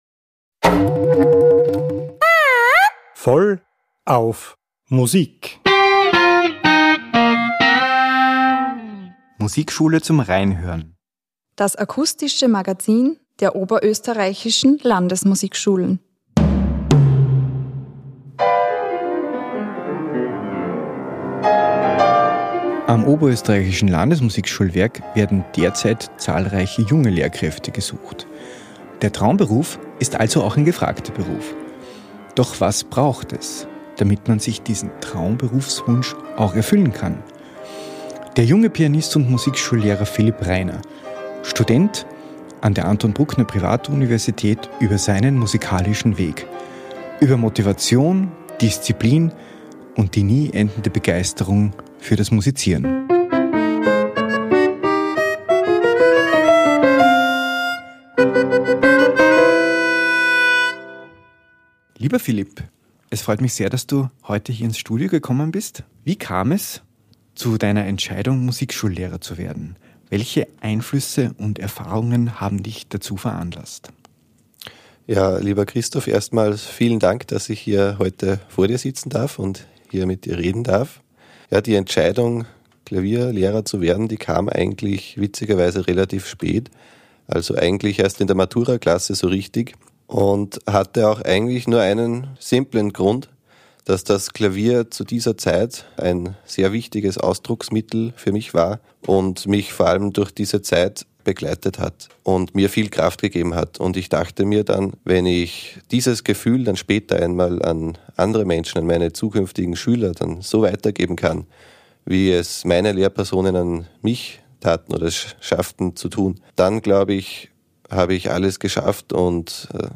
Wertvolle Informationen über den Studienalltag sowie Live-Aufnahmen seiner Auftritte an der Bruckneruni runden die Folge ab.